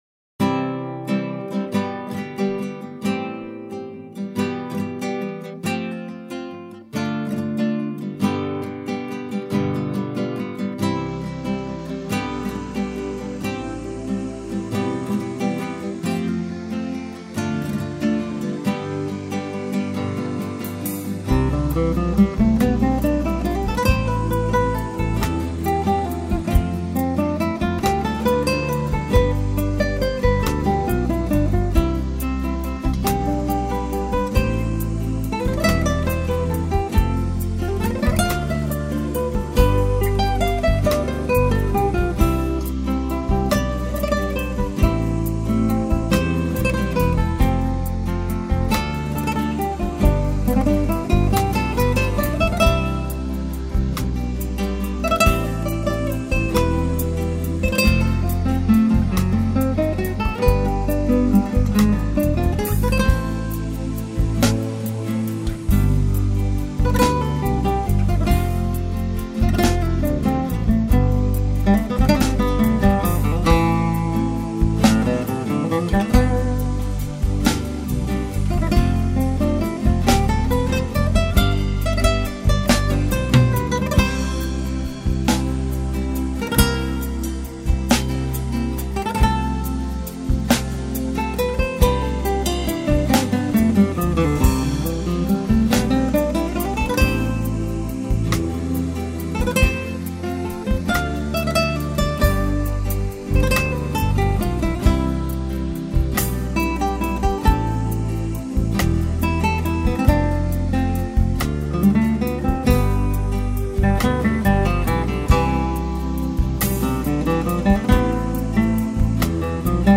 331   02:40:00   Faixa:     Instrumental